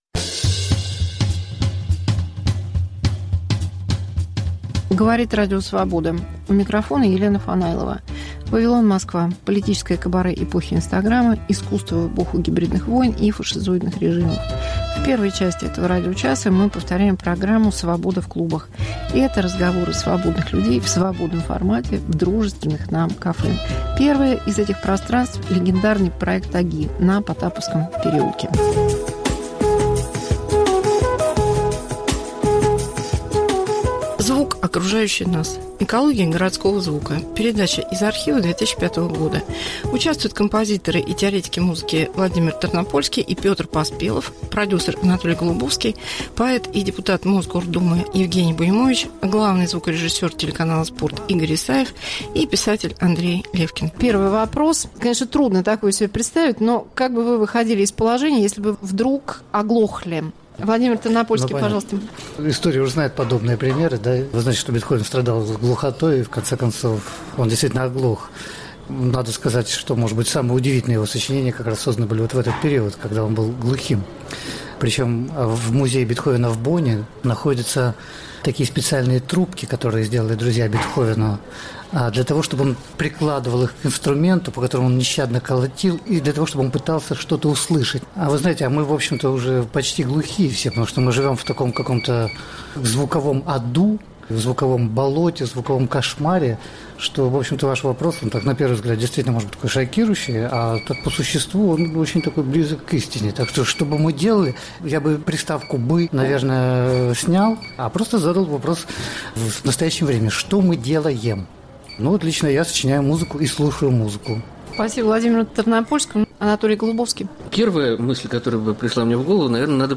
Фанайлова: Вавилон Москва. Экология звука. Интервью с Марией Степановой
Мегаполис Москва как Радио Вавилон: современный звук, неожиданные сюжеты, разные голоса. 1. Экология городского звука. 2.